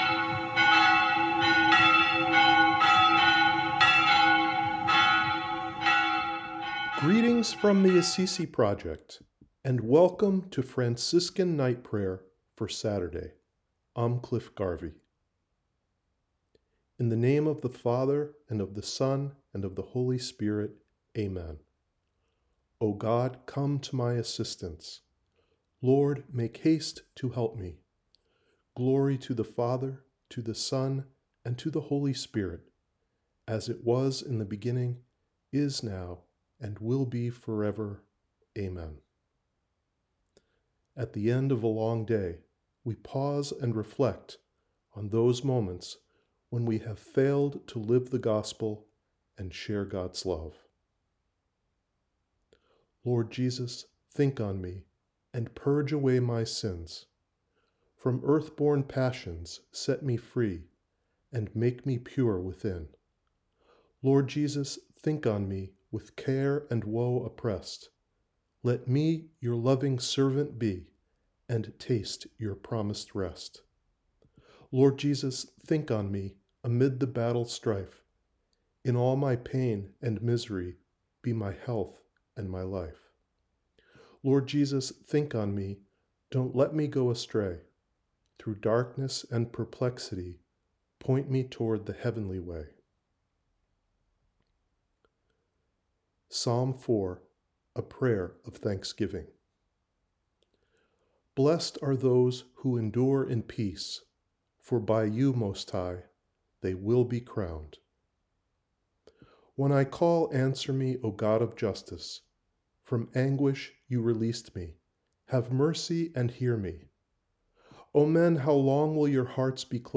ap-sat-night-prayer.wav